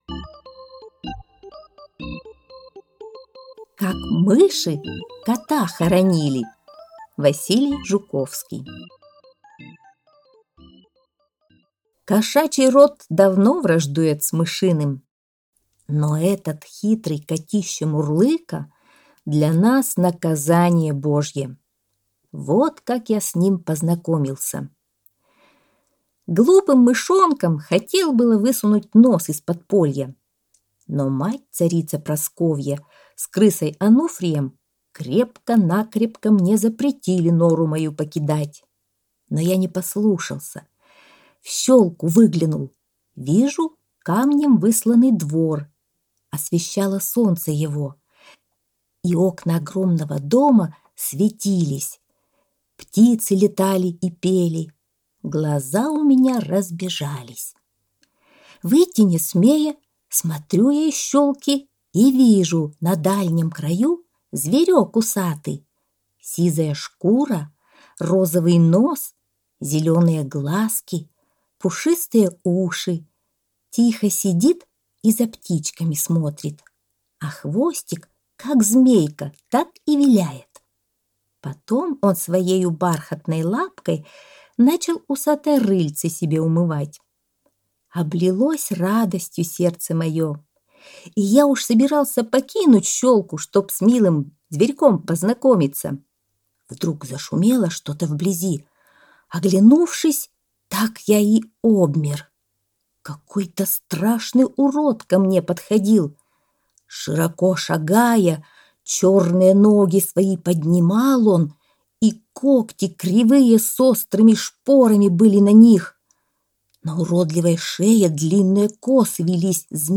Аудиосказка «Как мыши кота хоронили»